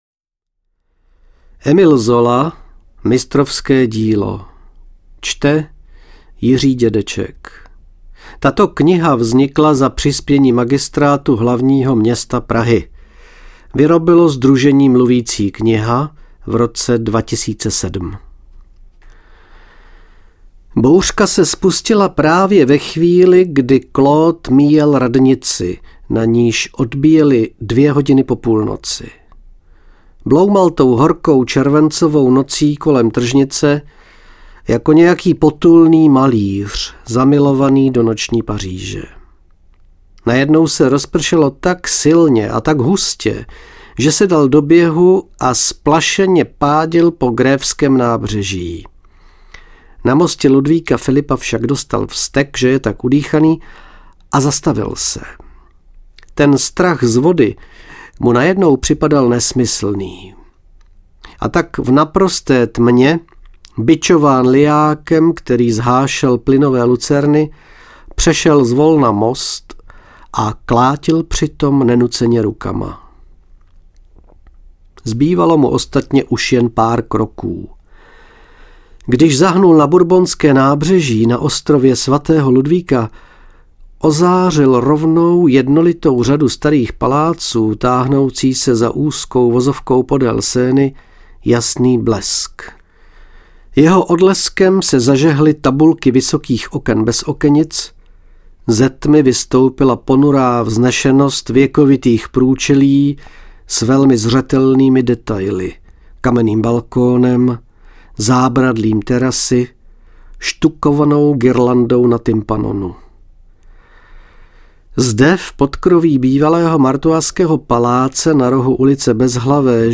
Mistrovské dílo Autor: Émile Zola Čte: Jiří Dědeček Vzniklo za přispění: Román s množstvím autobiografických prvků o věčném zápase umělců o vytvoření mistrovského díla a s realistickým hrdinou Claudem Lantierem.